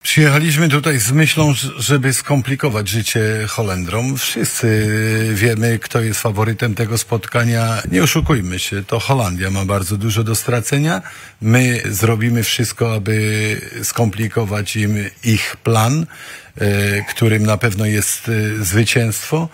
Zapowiada Urban.